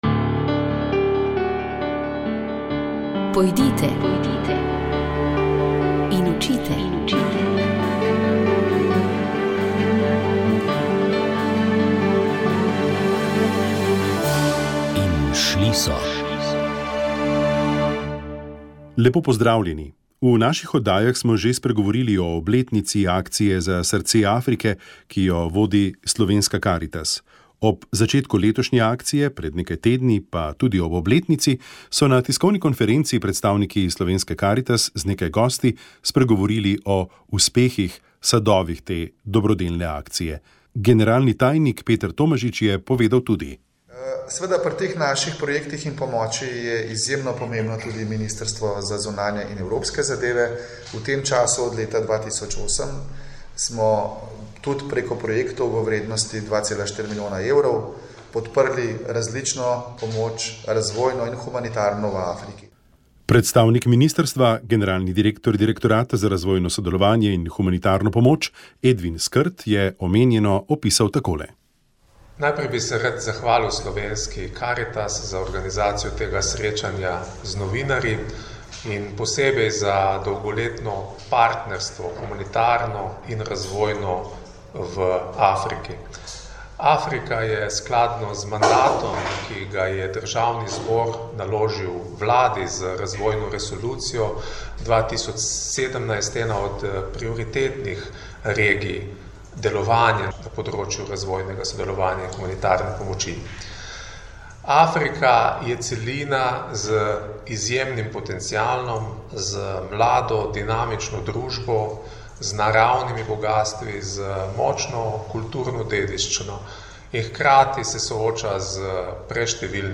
O vgradnji, vzdrževanju in servisu smo se pogovarjali z monterjem in serviserjem